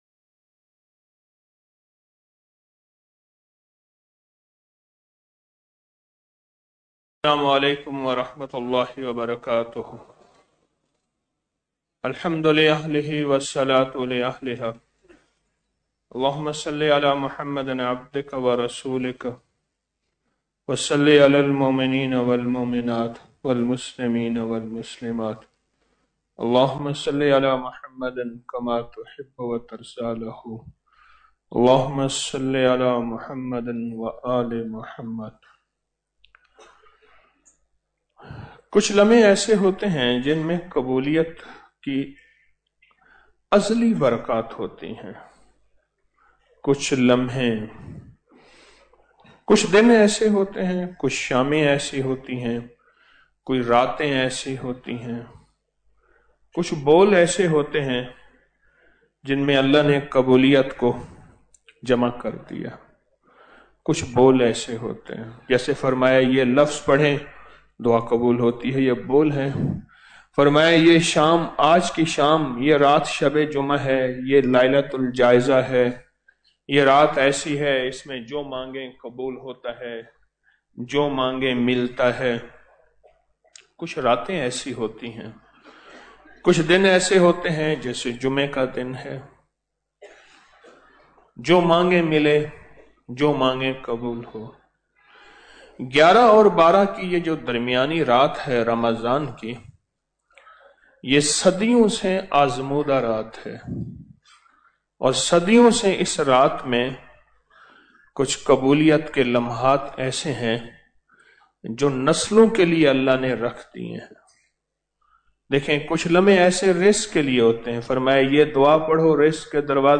Audio Speech - 12 Ramadan After Salat Ul Taraweeh- 12 March 2025